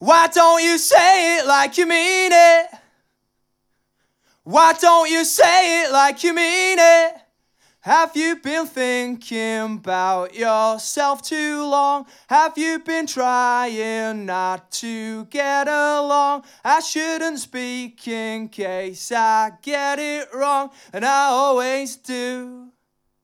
Échantillons sonores Audio Technica AT-4050
Audio Technica AT-4050 - mêski wokal